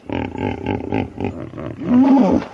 На этой странице собраны натуральные записи, которые подойдут для творческих проектов, монтажа или просто любопытства.
Хрюкающий звук бегемота